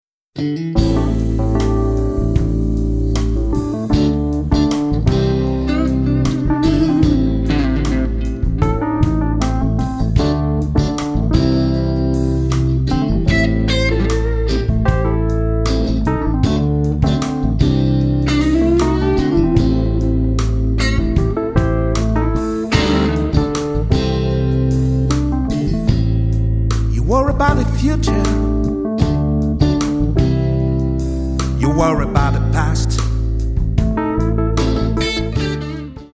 vocal, guitar
organ, piano
harp
bass
drums